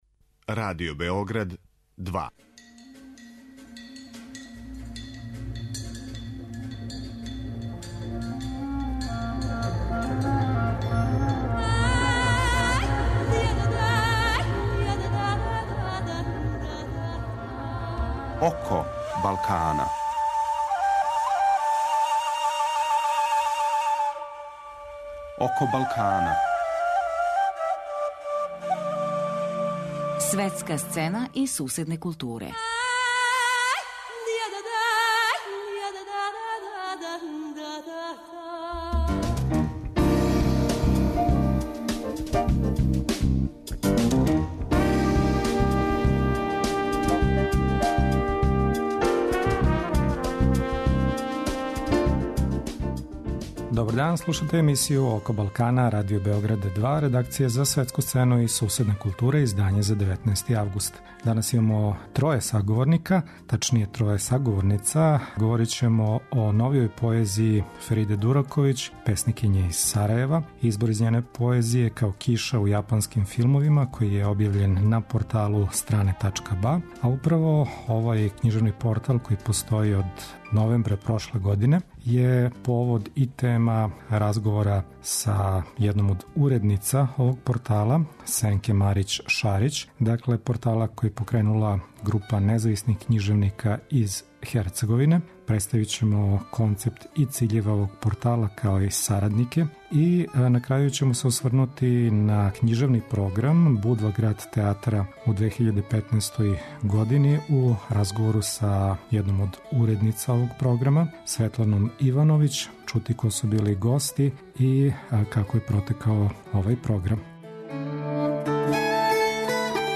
Радио Београд 2